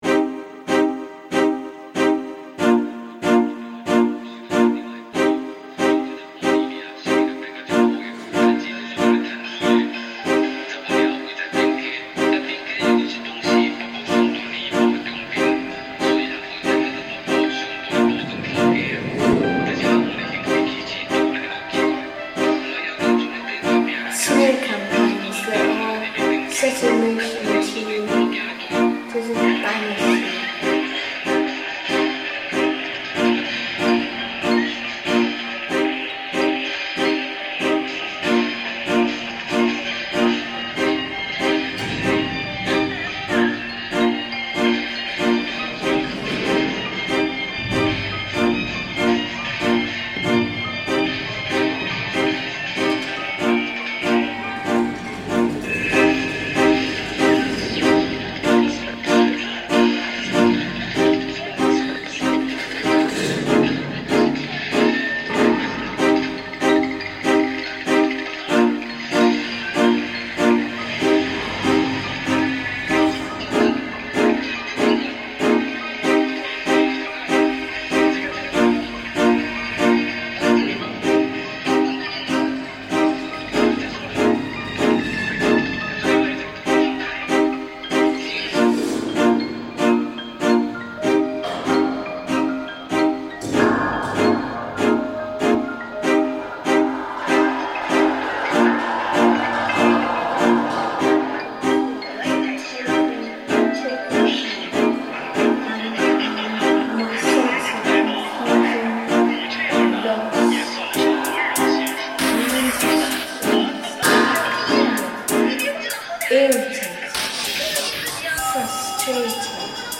This recording was originally broadcast in 1963, in the first year of Taiwan’s first terrestrial TV station, the Taiwan Broadcasting Company. "Look me in the eye" takes a short sequence of a mountain folk song sung by a women’s chorus, from the original 30-minute recording.
I layered sound in GarageBand, selecting and editing archival material, contemporary field recordings, overheard dialogue and digital loops, to build aural glimpses of cultural, temporal, and geographic landscapes. By positioning the mountain folk song in dialogue with a recording of a metro train in a Tokyo tunnel, I form a sonic relationship between the mountain above, the underground below, and the distant flatland of my Newhaven studio from which the piece is composed.
In contrast, the Tokyo metro recording introduces a dense, enclosed soundscape. The arrival and departure of a train, with its mechanical rhythm and reverberant tunnel, defines movement, efficiency, and compression.